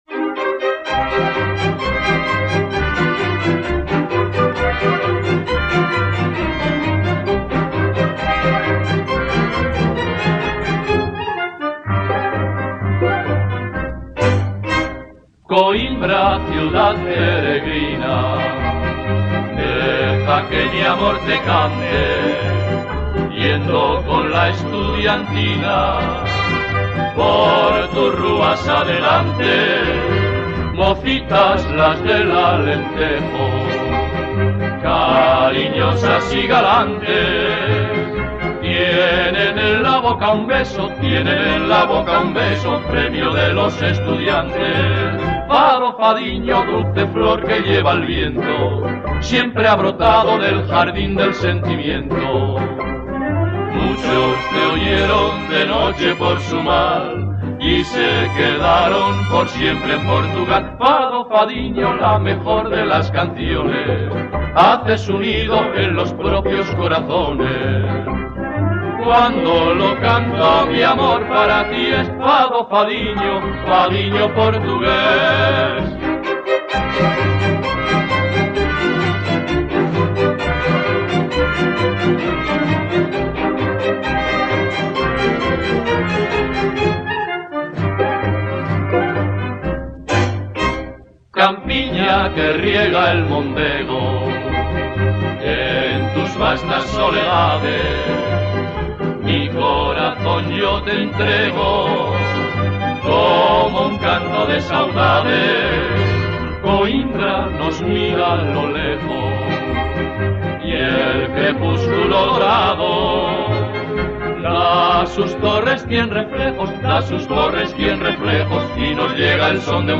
Fado Cancion